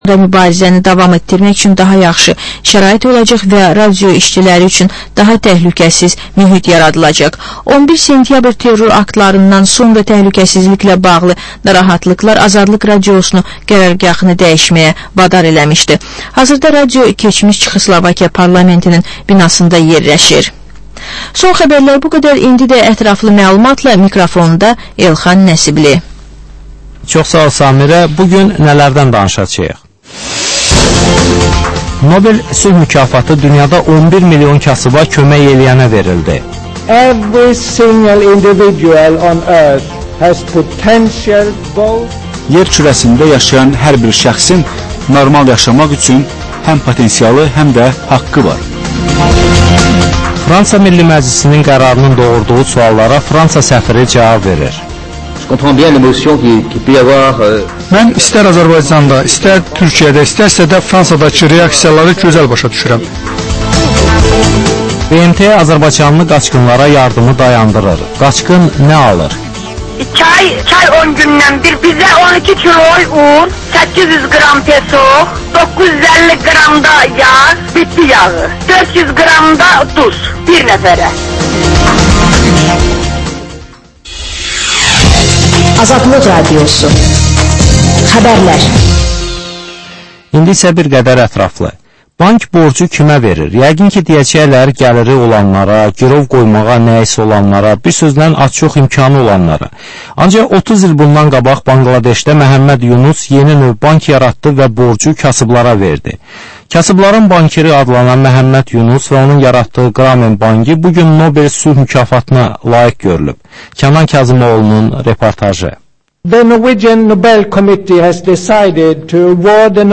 Reportaj, müsahibə, təhlil